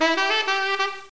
SAX4.mp3